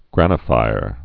(grănə-fīr)